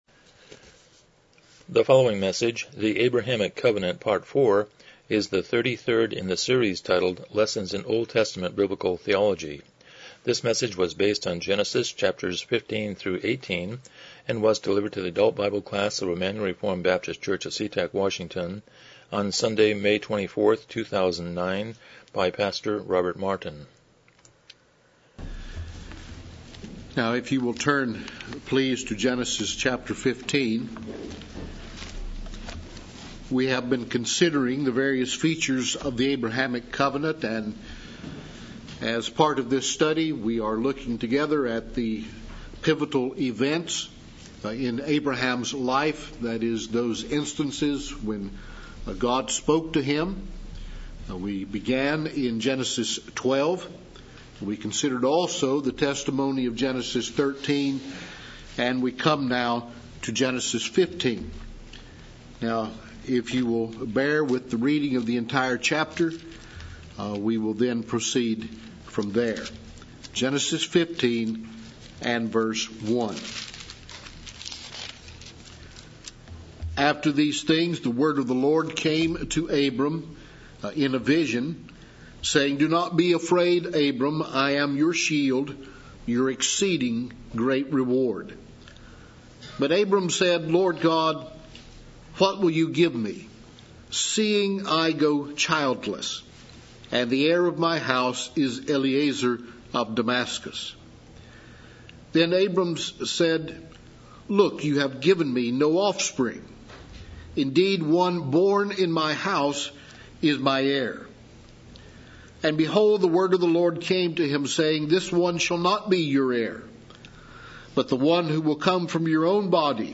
Passage: Genesis 15:1-18:33 Service Type: Sunday School